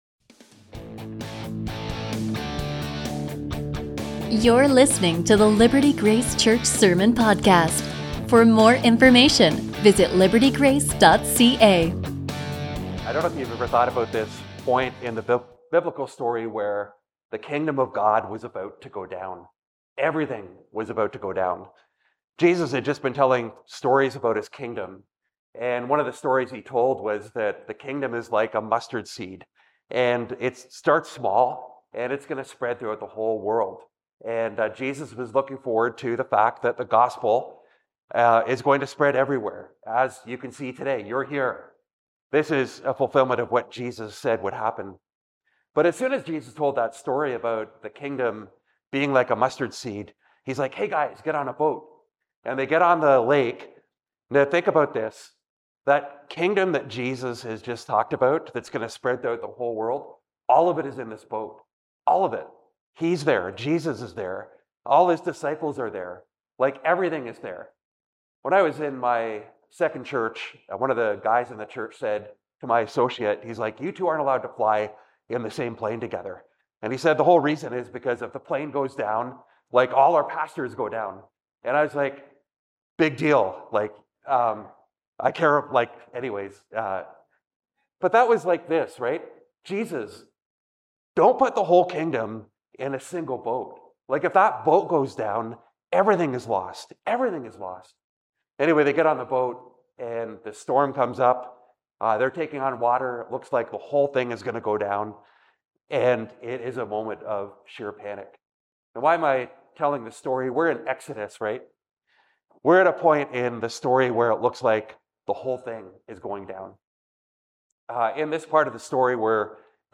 A sermon from Exodus 1-28